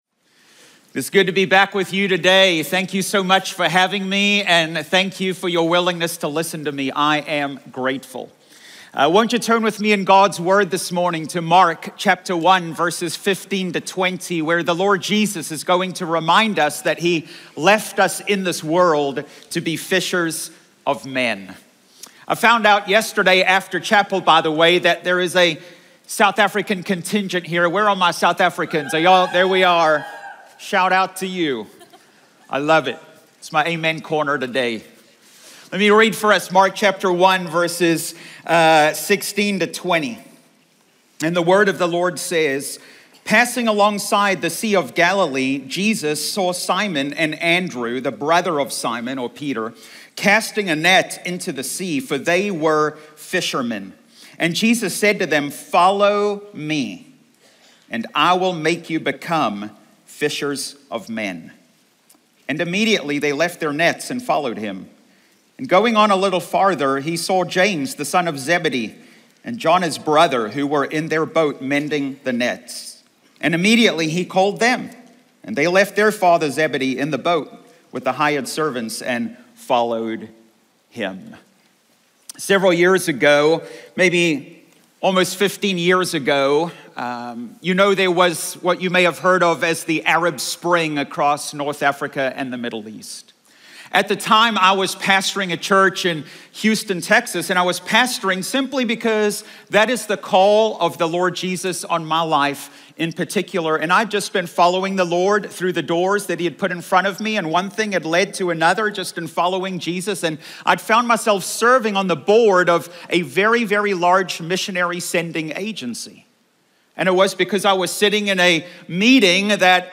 Cedarville University Chapel Message